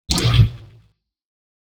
gravity_gun_freeze.wav